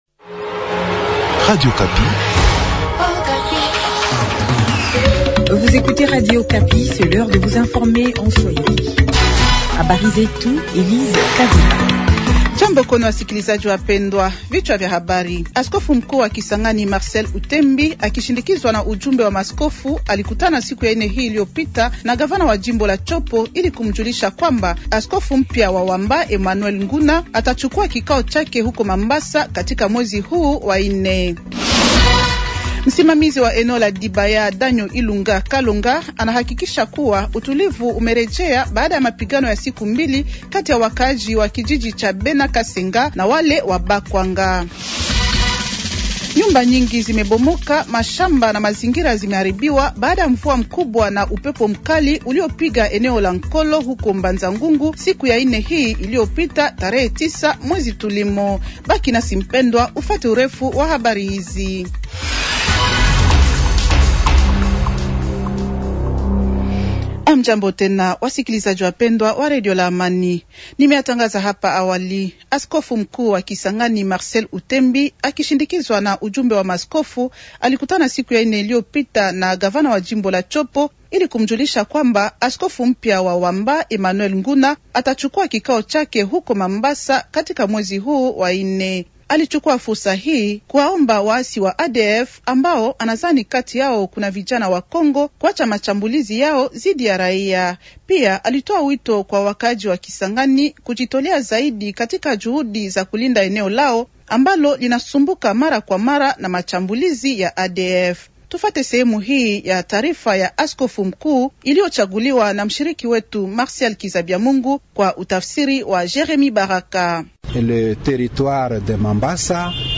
Journal swahili de vendredi soir, 100426